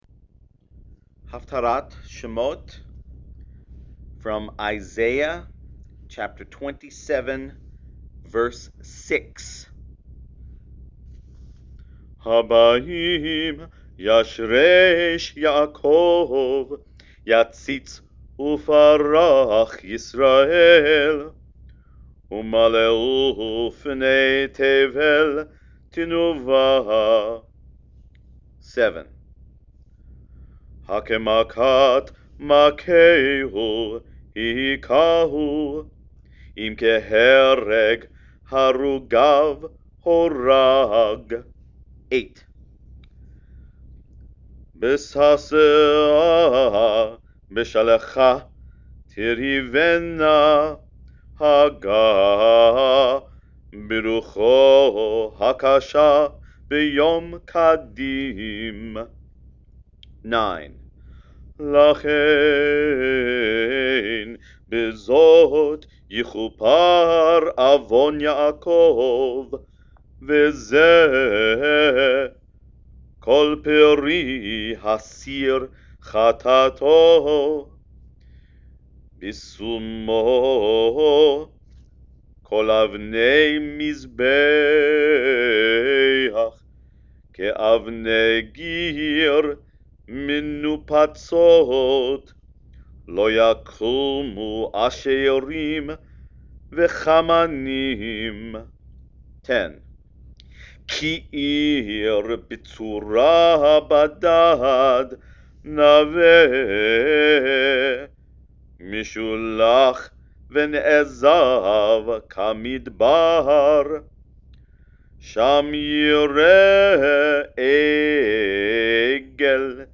Haftarah